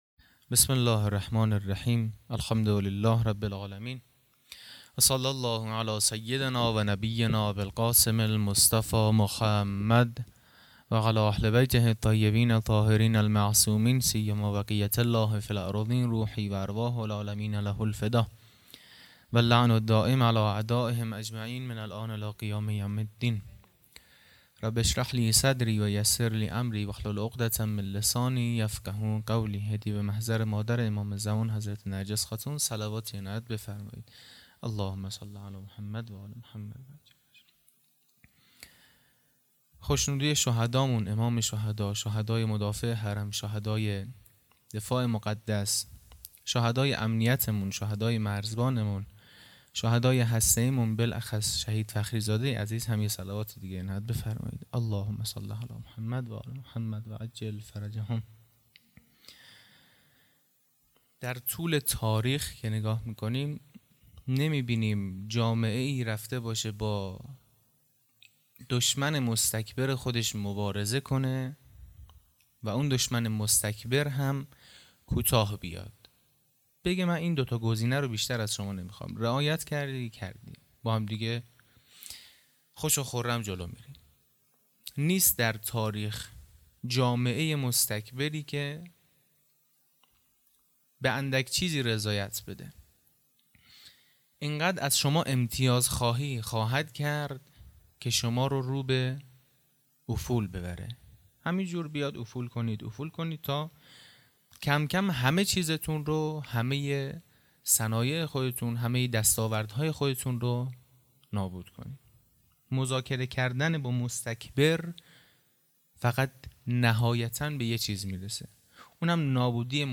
خیمه گاه - هیئت بچه های فاطمه (س) - سخنرانی| پنج شنبه 13 آذر 99